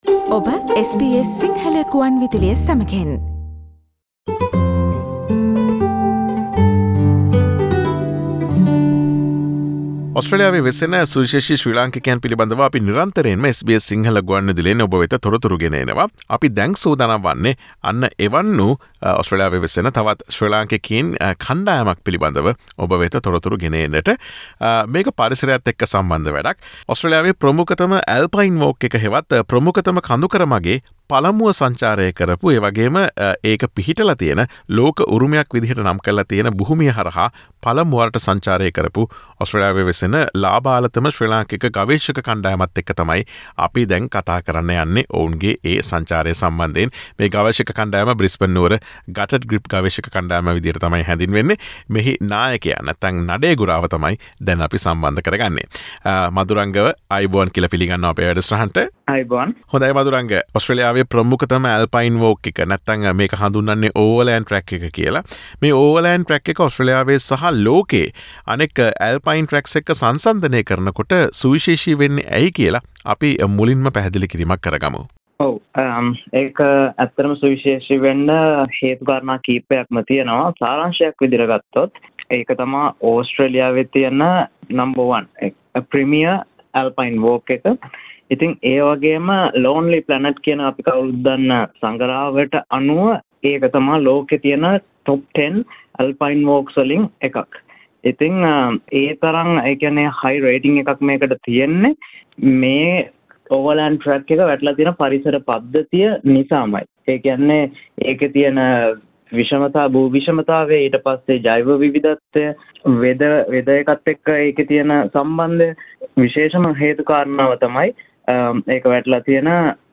ඔස්ට්‍රේලියාවේ ප්‍රමුඛතම කඳුකර මග වන Overland Track එකේ පළමුව සංචාරය කළ ඔස්ට්‍රේලියාවේ වෙසෙන ලාබාලතම ශ්‍රී ලාංකික ගවේෂක කණ්ඩායම බවට පත්වූ බ්‍රිස්බේන් නුවර GUTD GRIP ගවේෂක කණ්ඩායම සමග SBS සිංහල ගුවන් විදුලිය සිදුකළ සාකච්ඡාව.